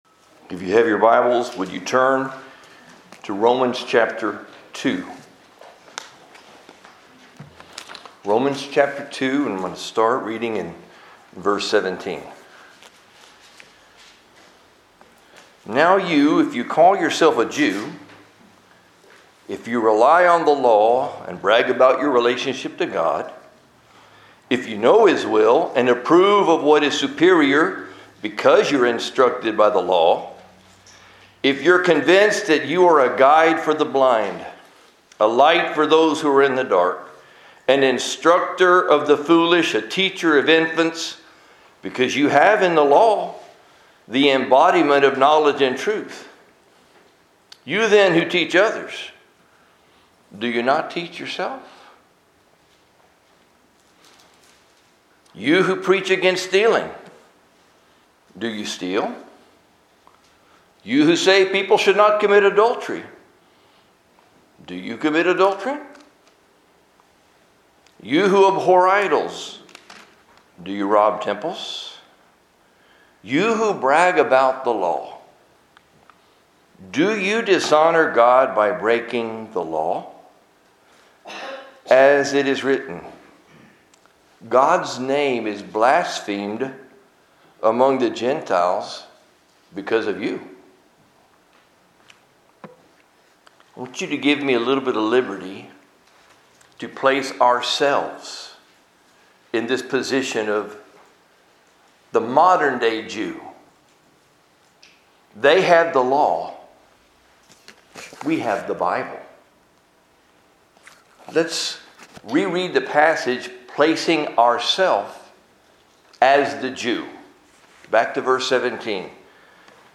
Sunday Message - University Park Baptist